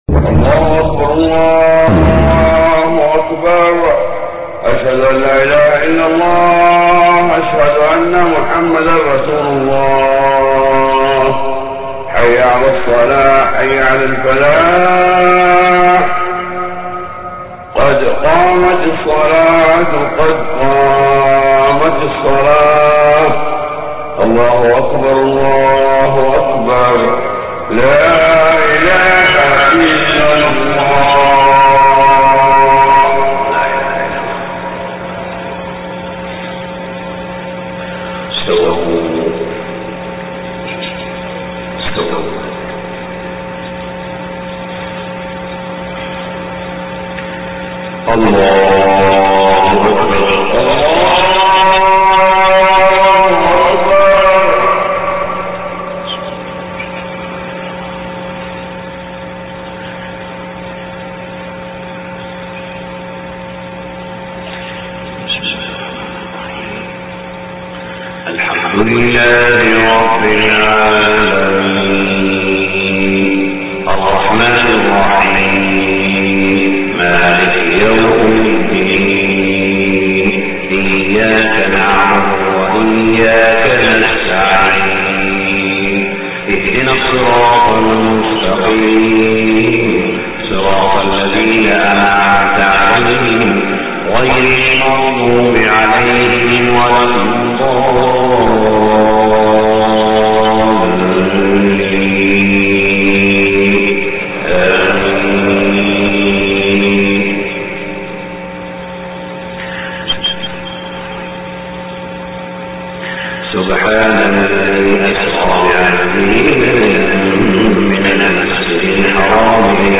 صلاة الفجر 21 محرم 1430هـ فواتح سورة الإسراء 1-22 > 1430 🕋 > الفروض - تلاوات الحرمين